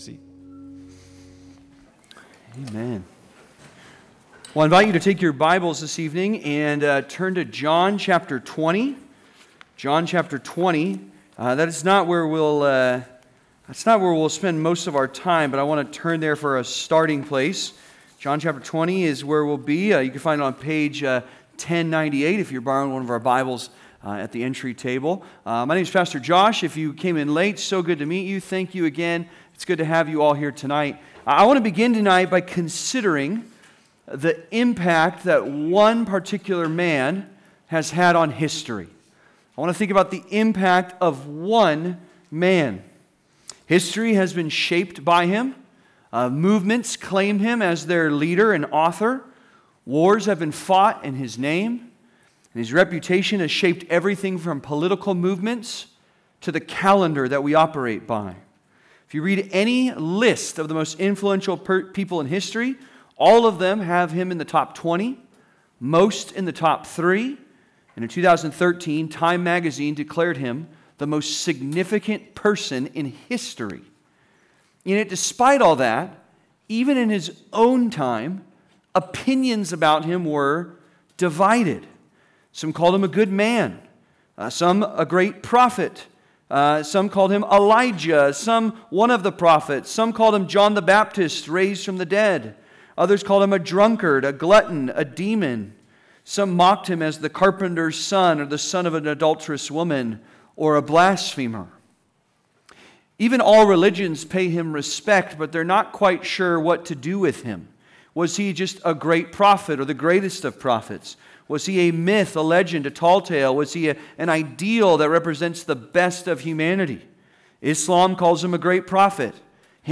(Sermon) - Compass Bible Church Long Beach
Special Saturday Service